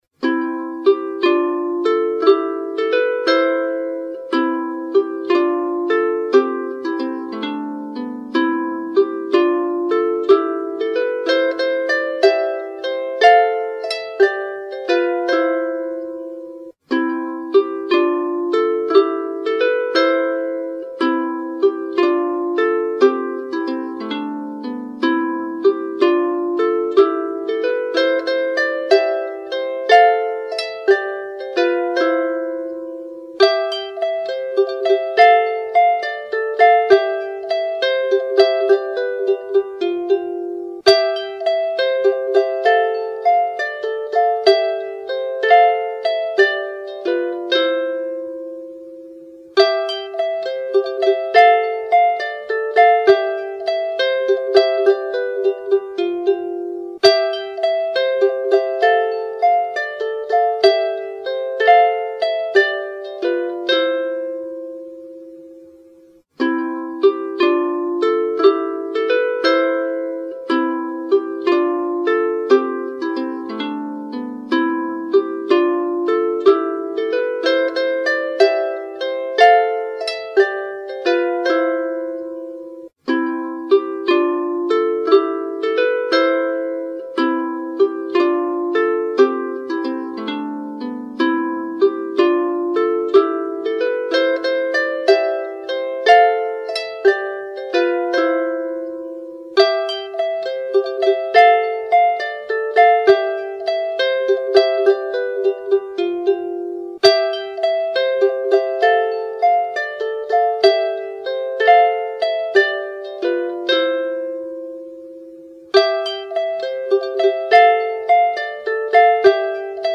It’s agreed that the form is a jig, but that’s about it.
in C, on lyre. I hope you enjoy this cheerful melody!